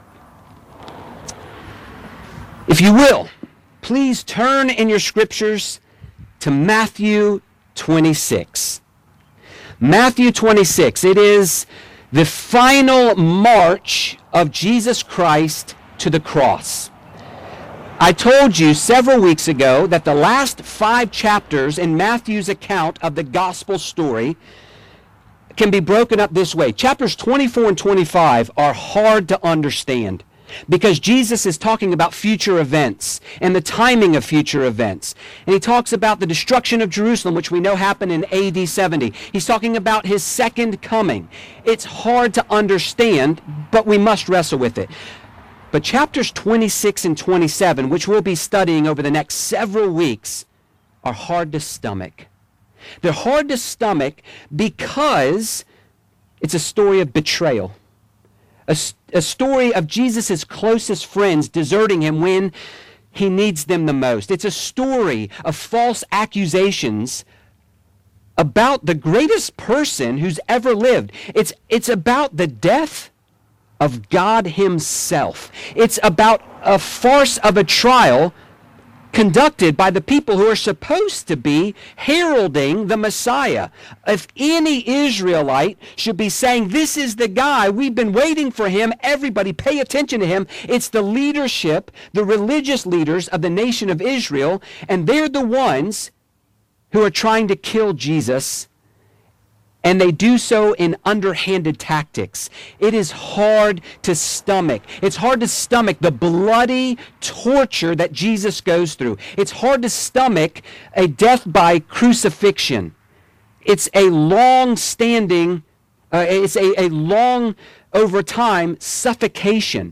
Grace Presbyterian Church, PCA Sermons